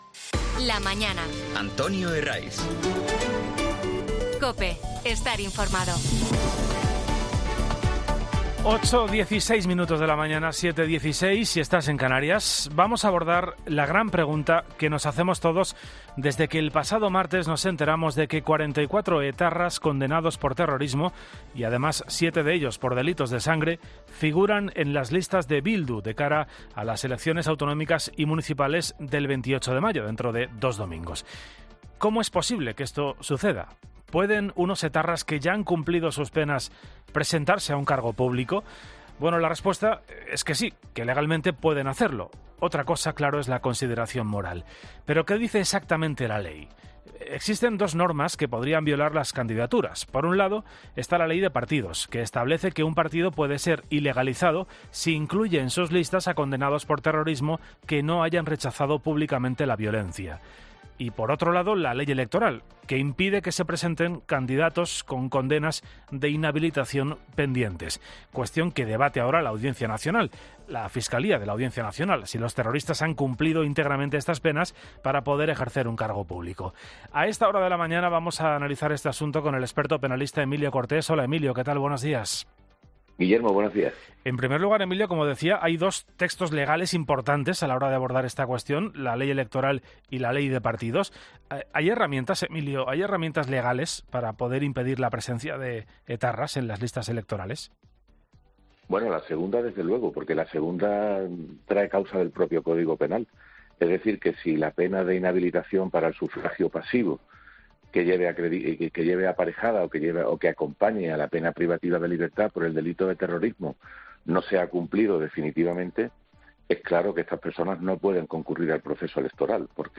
experto penalista, en La Mañana Fin de Semana